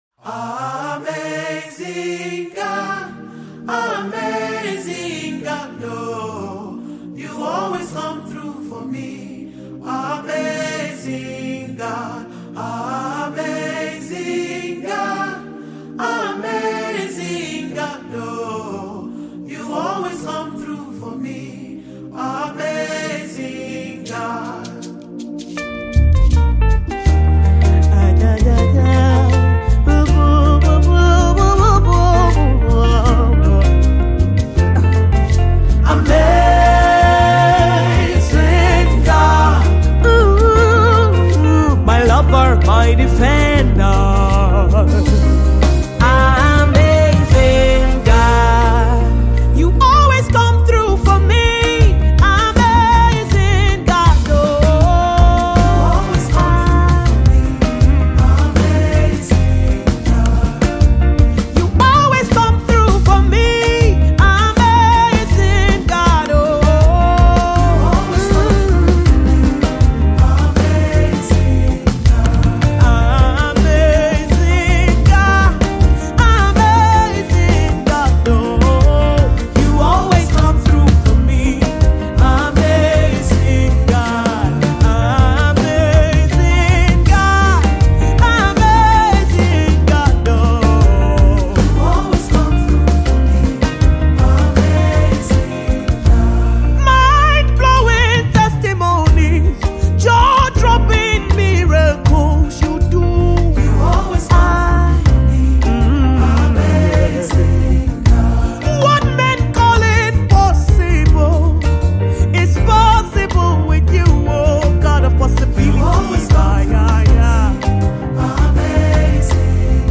Chiristian song
Nigerian talented gospel singer, songwriter and artist
song of praise
gospel song